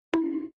bonk.aac